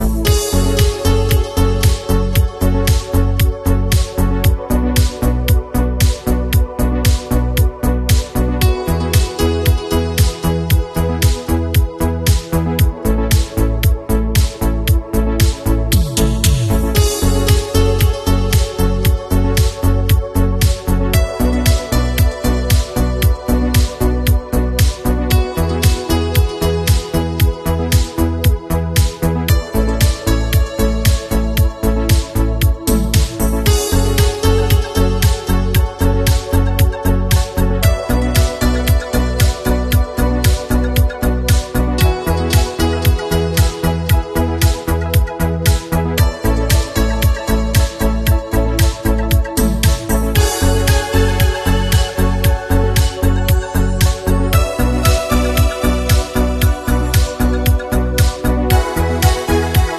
CD player technics/ EuroMartina style